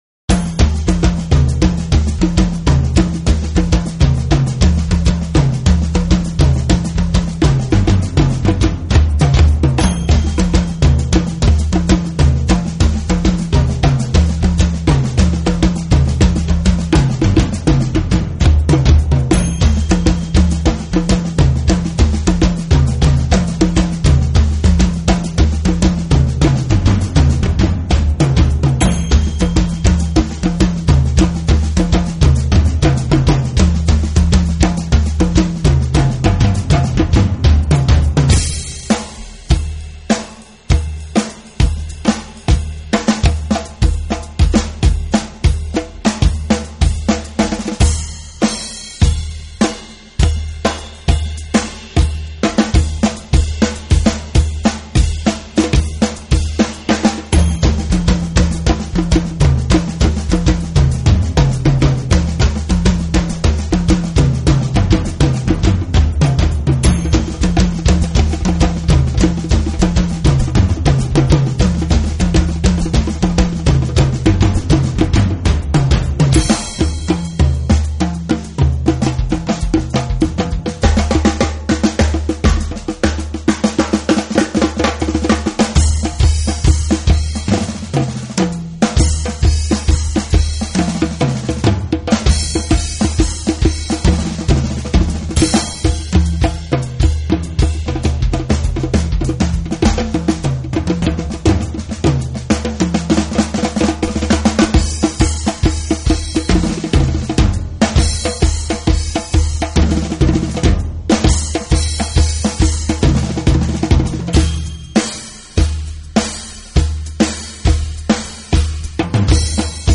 【Fusion爵士】
Fusion风格吉他手